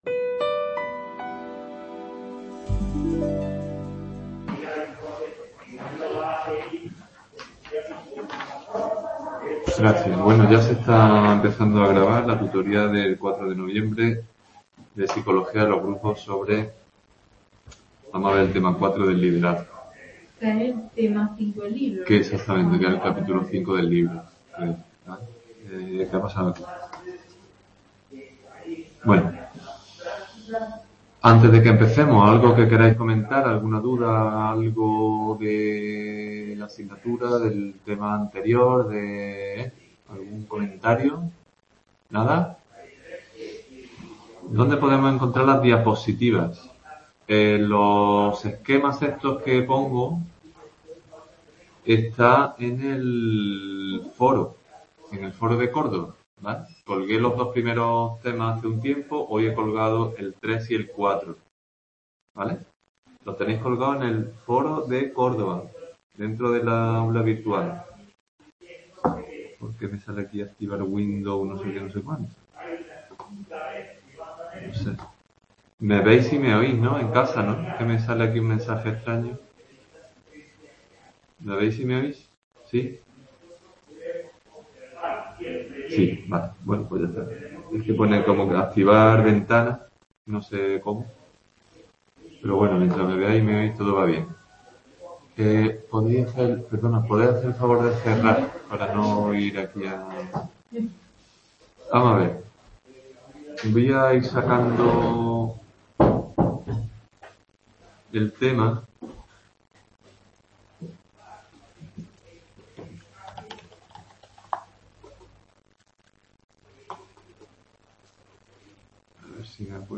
Tutoría Grupos. Tema 4. Liderazgo | Repositorio Digital